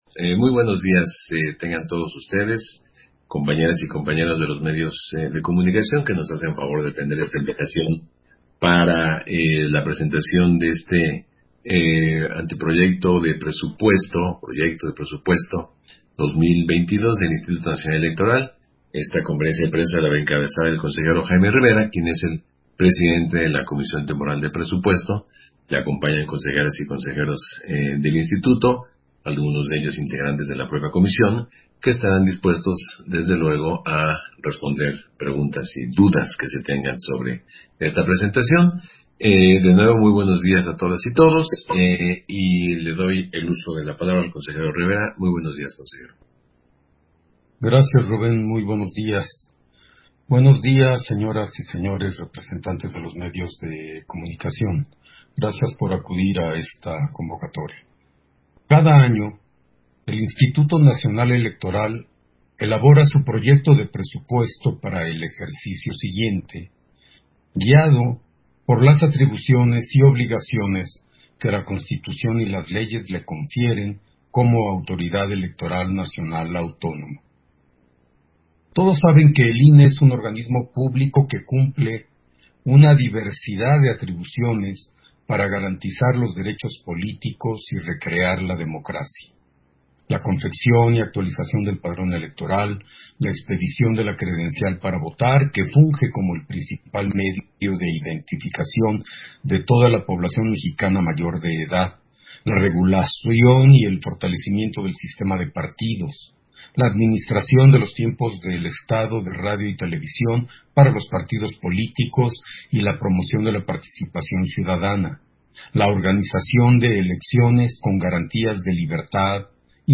200821_AUDIO_CONFERENCIA-DE-PRENSA-PRESUPUESTO-2022-DEL-INE